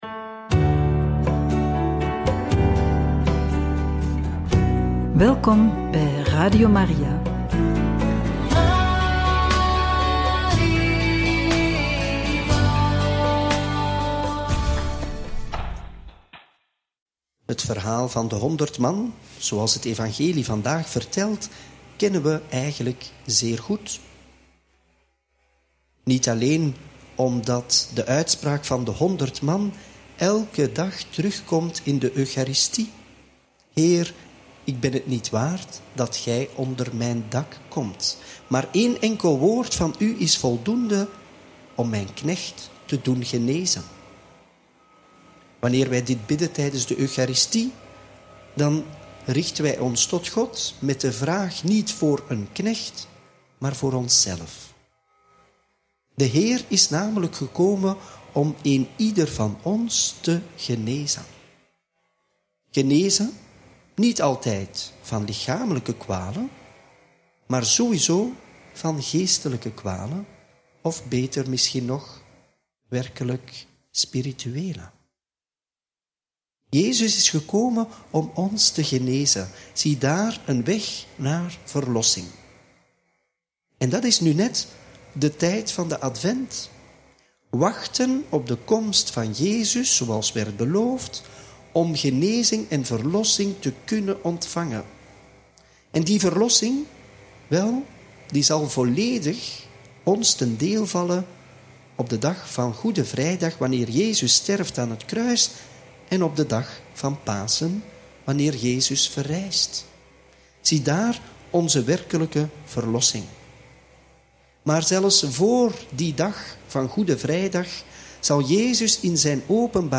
Homilie bij het Evangelie van maandag 2 december 2024 – Mt. 8, 5-11